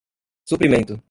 /su.pɾiˈmẽ.tu/